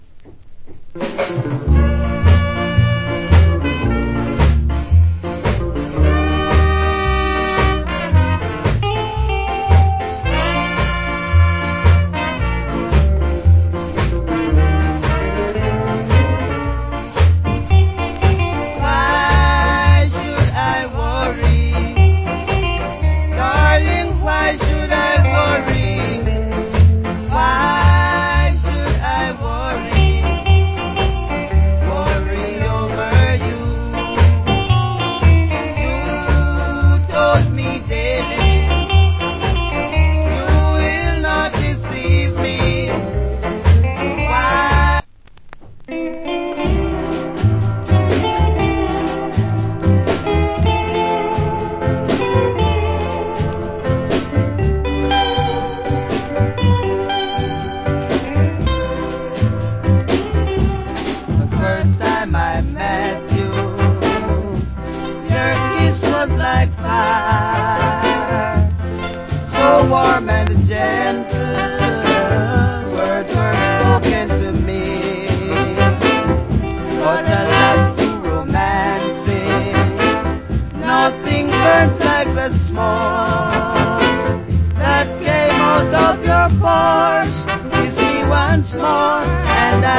Good Duet Ska Vocal.